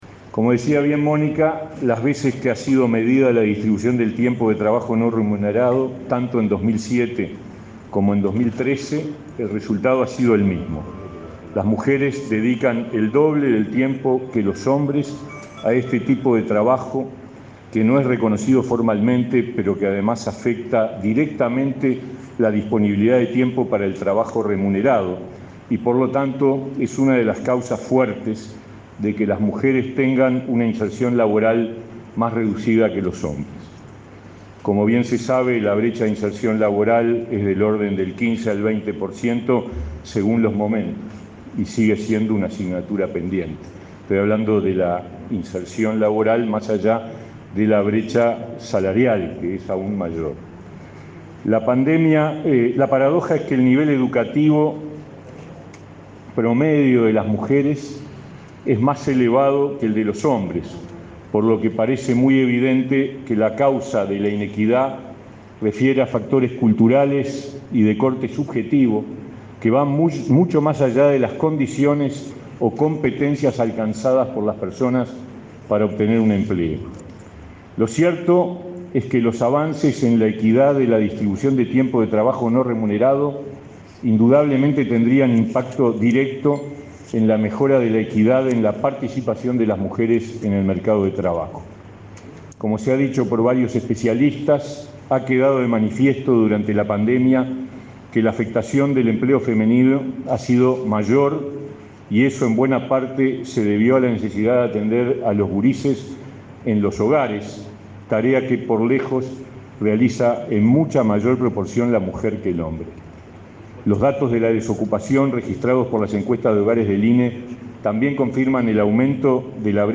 Acto oficial del Gobierno por el Día Internacional de la Mujer
Acto oficial del Gobierno por el Día Internacional de la Mujer 08/03/2021 Compartir Facebook Twitter Copiar enlace WhatsApp LinkedIn Durante la ceremonia de este lunes 8, que incluyó la participación del presidente Luis Lacalle Pou y de la vicepresidenta, Beatriz Argimón, el ministro de Trabajo y Seguridad Social, Pablo Mieres, adelantó que en próxima encuesta de hogares se medirá el uso del tiempo no remunerable desde una perspectiva de género.